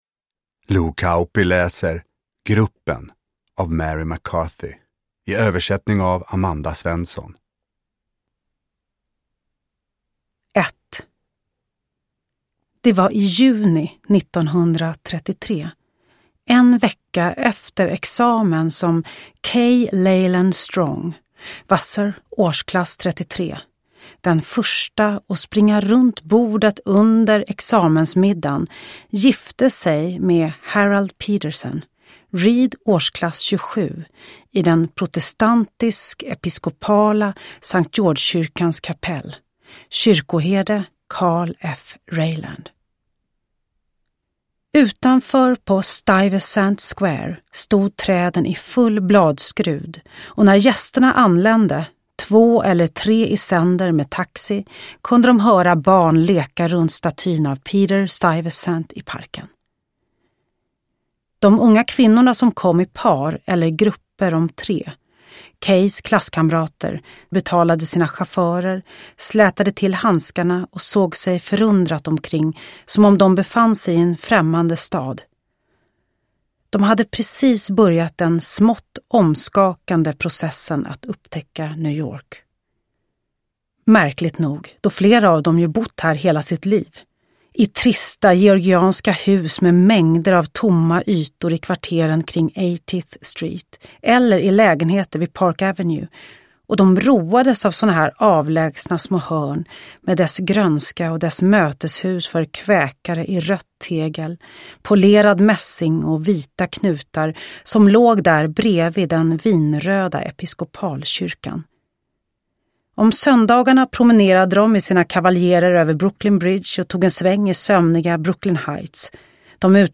Gruppen – Ljudbok – Laddas ner
Uppläsare: Lo Kauppi